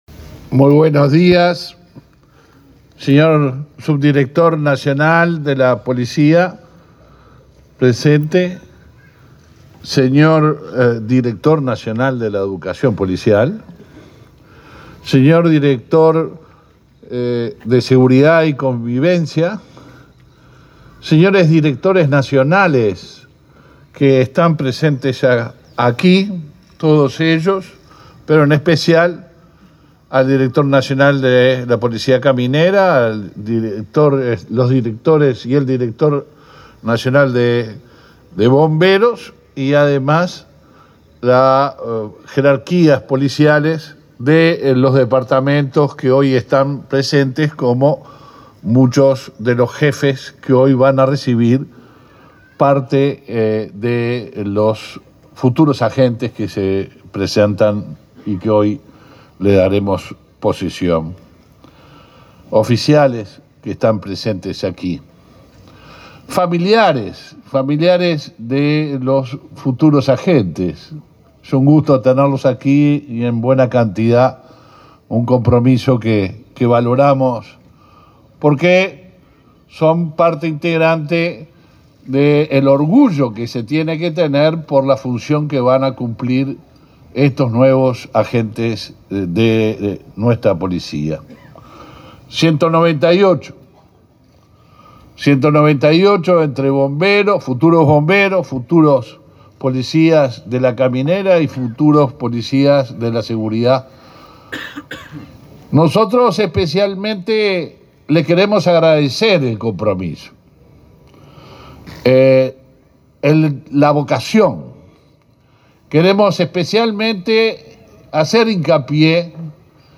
Palabras del ministro del Interior, Luis Alberto Heber
Palabras del ministro del Interior, Luis Alberto Heber 01/09/2022 Compartir Facebook X Copiar enlace WhatsApp LinkedIn Este jueves 1.°, el ministro del Interior, Luis Alberto Heber, participó del acto de egreso de estudiantes de la Escuela Nacional de Educación Policial.